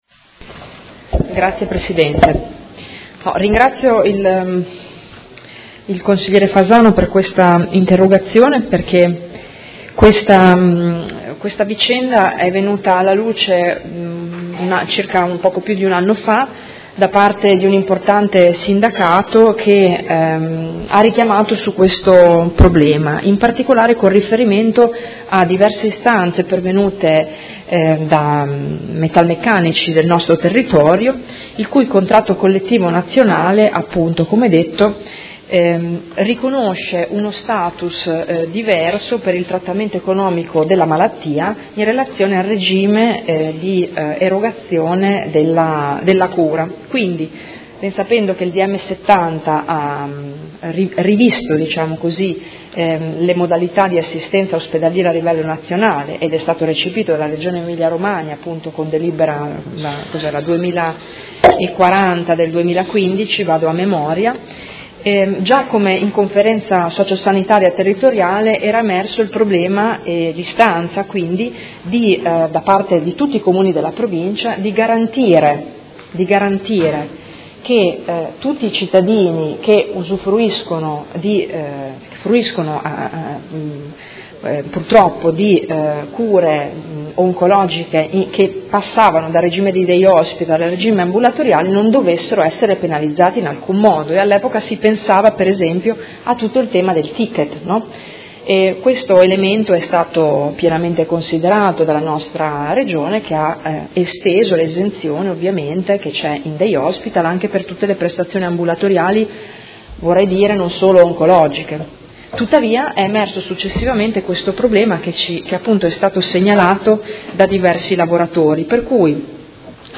Seduta del 17/05/2018. Risponde a interrogazione del Consigliere Fasano (PD) avente per oggetto: Copertura economica per lavoratori sottoposti a cure oncologiche